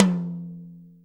• Long Room Reverb Tom One Shot F Key 10.wav
Royality free tom single hit tuned to the F note. Loudest frequency: 1338Hz
long-room-reverb-tom-one-shot-f-key-10-Tzj.wav